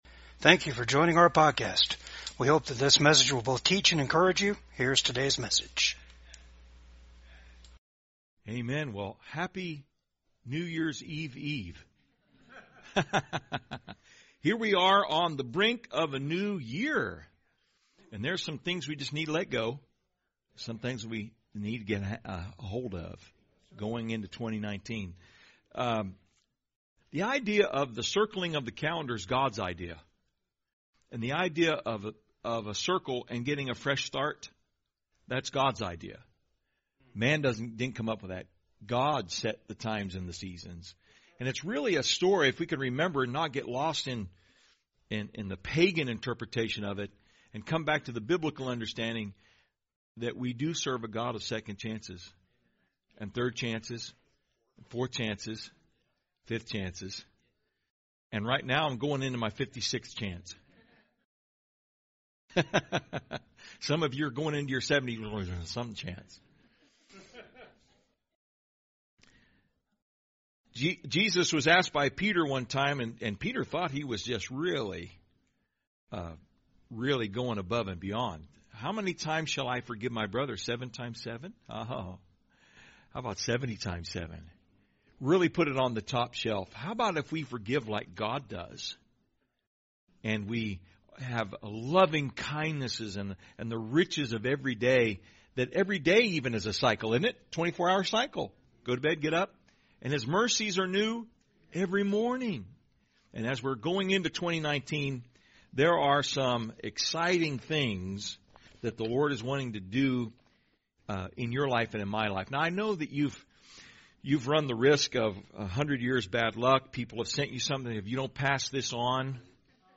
John 10:10 Service Type: VCAG SUNDAY SERVICE 1.